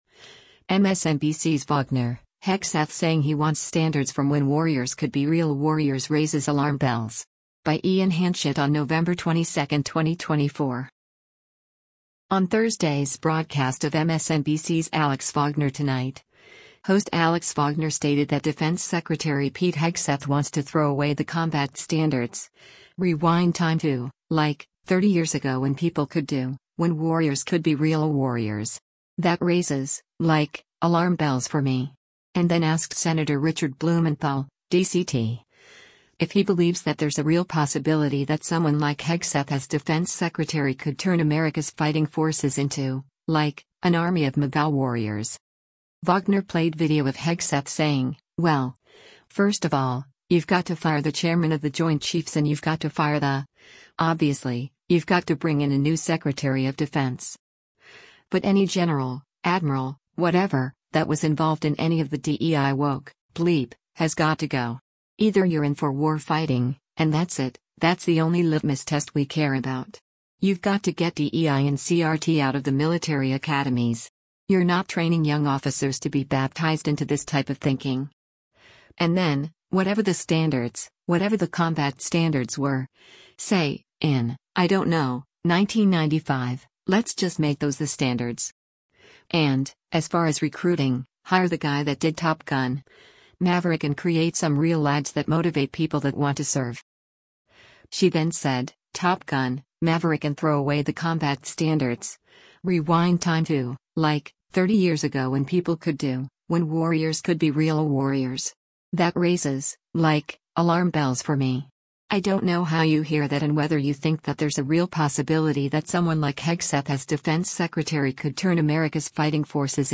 On Thursday’s broadcast of MSNBC’s “Alex Wagner Tonight,” host Alex Wagner stated that Defense Secretary Pete Hegseth wants to “throw away the combat standards, rewind time to, like, 30 years ago when people could do — when warriors could be real warriors.